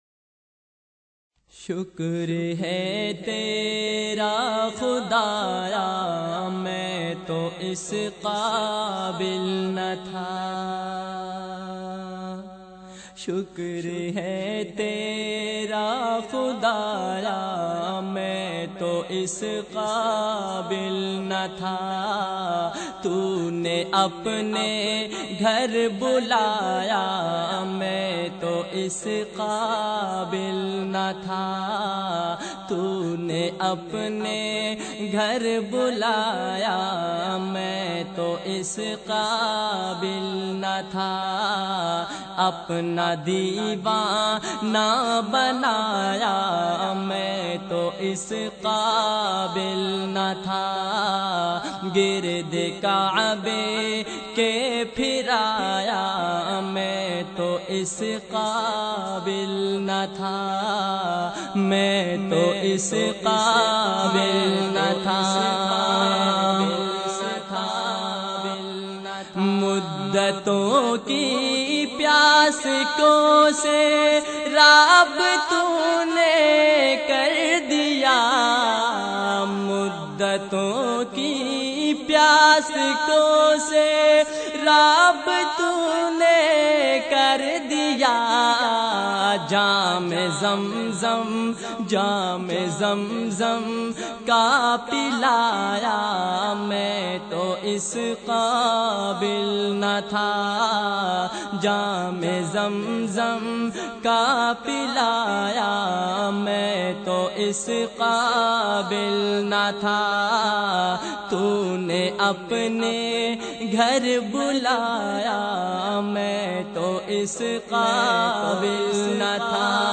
Heart-Touching Voice